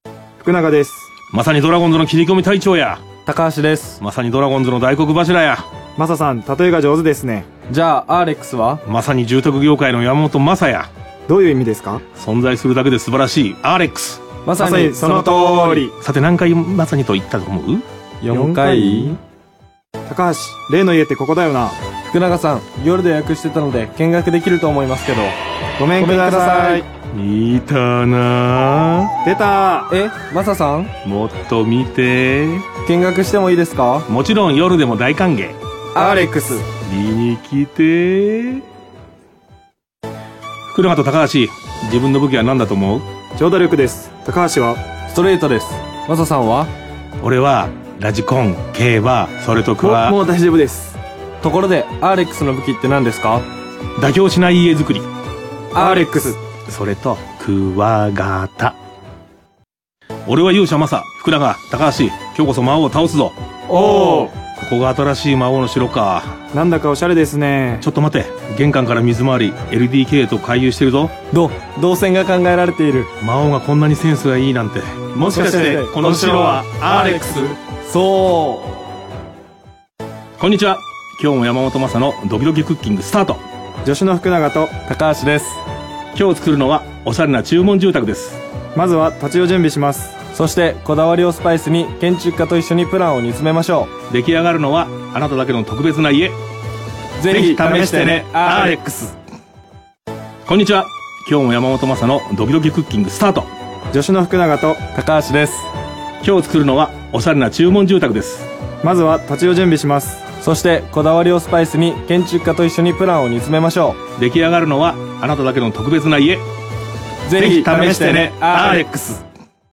例年通り初戦はアーレックス1社提供でドラゴンズの選手が出演する番組独自のCMとそのメーキングを放送。今年はドラゴンズOBの山本昌、現役選手からは福永裕基と高橋宏斗が参加。計11パターンのCMがオンエアされました。某アイドルの”構文”を使うなどトレンドを取り入れたネタもありますね。